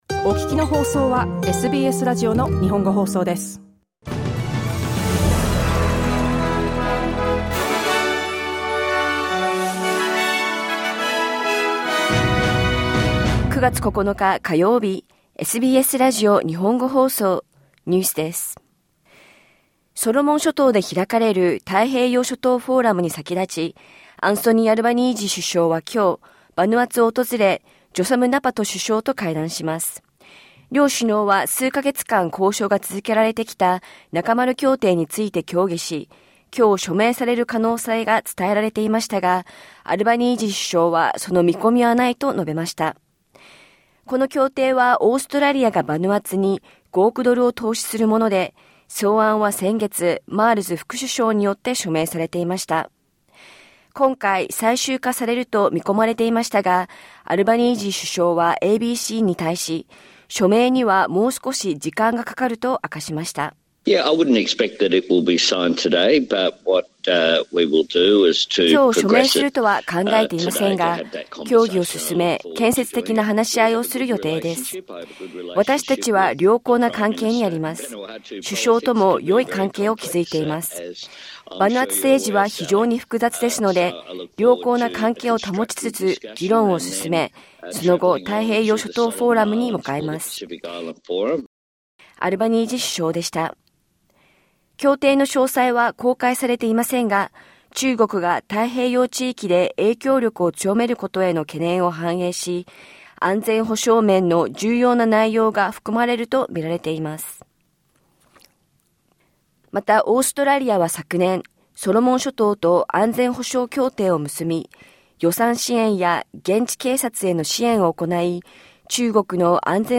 SBS日本語放送ニュース9月9日火曜日